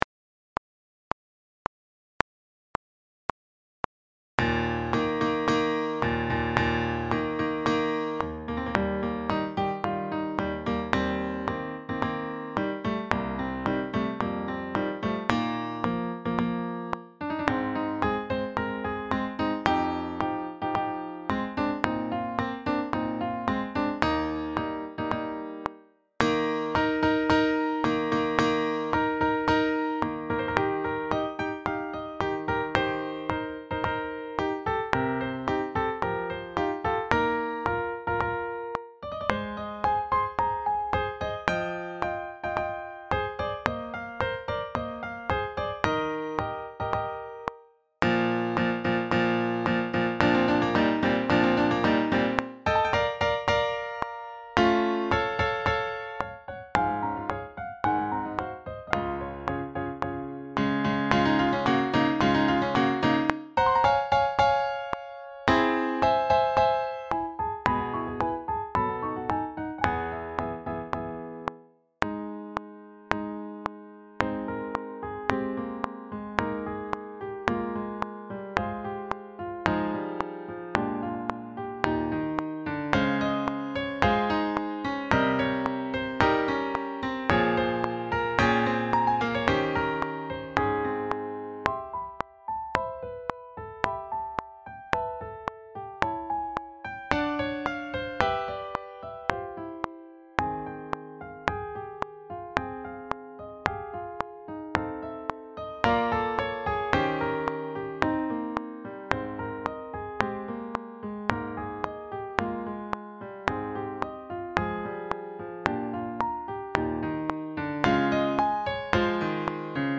Sax Quartets
Written in celebration of his own 25th Wedding Anniversary, Grieg's piano piece "Hochzeitstag auf Troldhaugen" (literally "Wedding Anniversary at Troldhaugen" but often known as "Wedding Day at Troldhaugen") is a joyful march and interlude with a simple yet memorable theme.
This arrangement is a simplification of the piano score but still gives all four saxes plenty to play across the whole range of the instrument.
Backing track
162-4-wedding-day-at-troldhaugen-quartet-backing-track.mp3